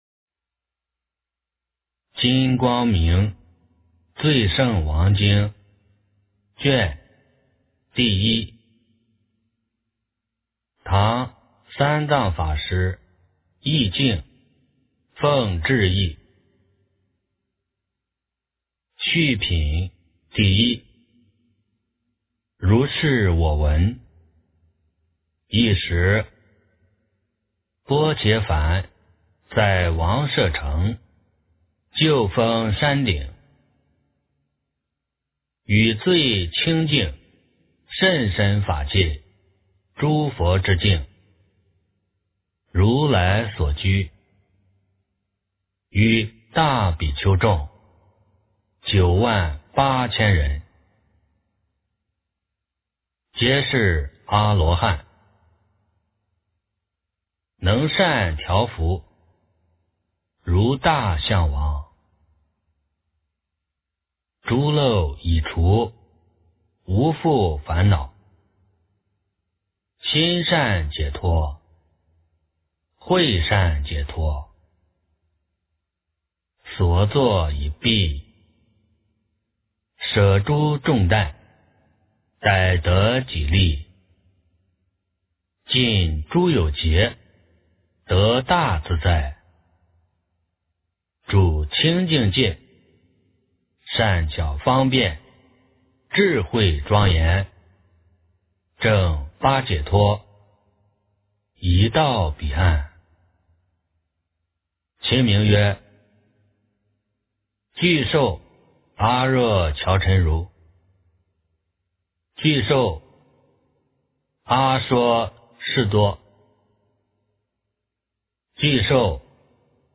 诵经
佛音 诵经 佛教音乐 返回列表 上一篇： 地藏经 下一篇： 地藏菩萨本愿功德经 相关文章 般若佛母心咒--佛音佛语 般若佛母心咒--佛音佛语...